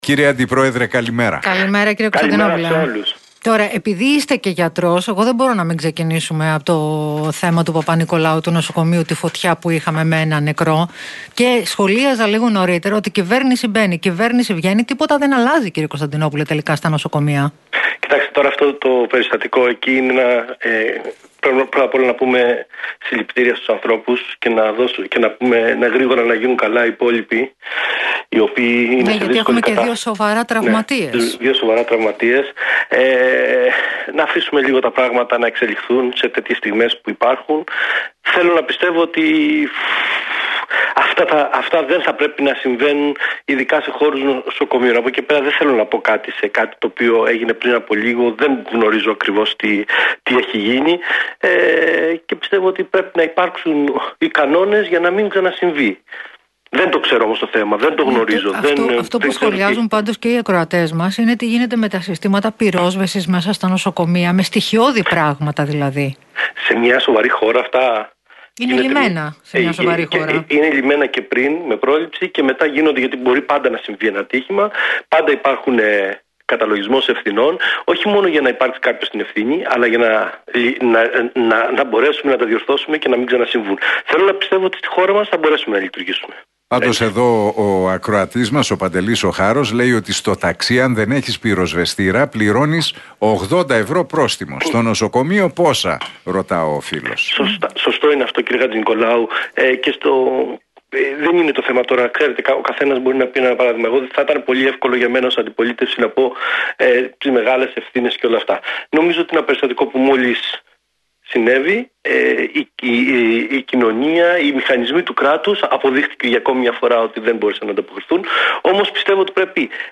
Ο βουλευτής του ΚΙΝΑΛ – ΠΑΣΟΚ, Οδυσσέας Κωνσταντινόπουλος, μιλώντας στον Realfm 97,8